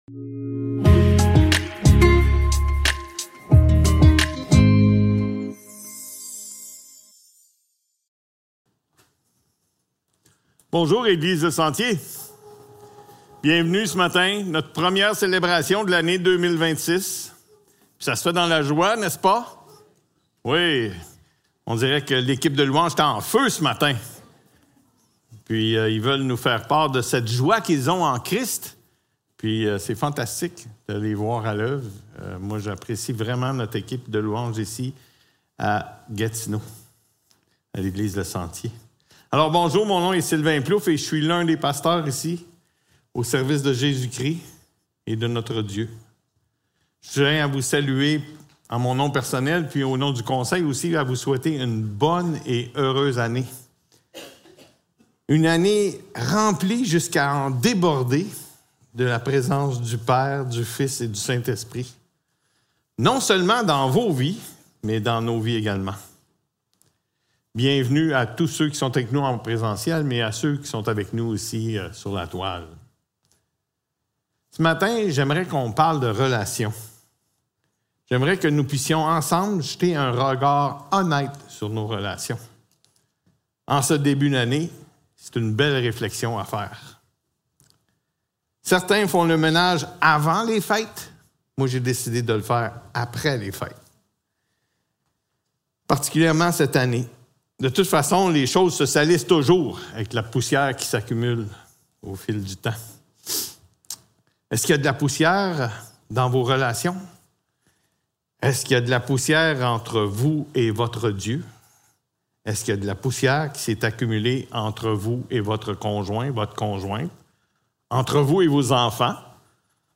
Célébration dimanche matin Description